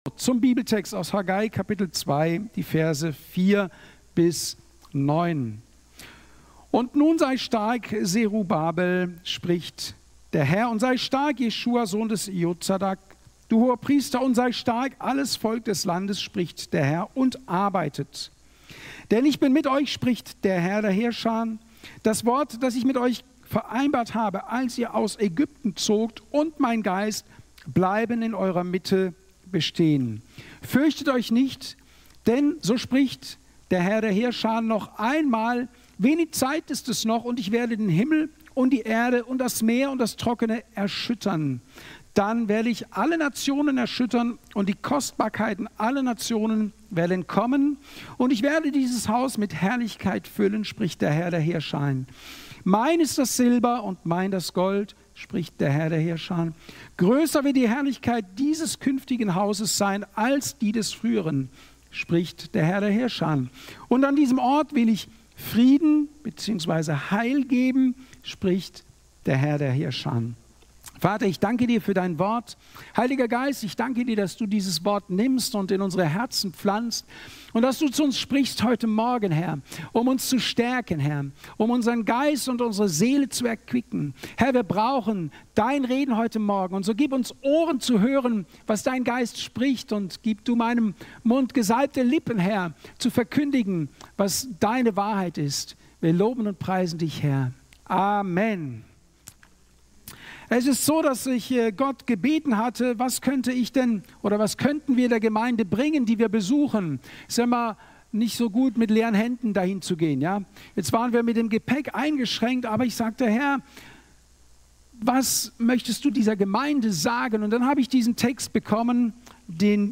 13.04.2025 Ort: Gospelhouse Kehl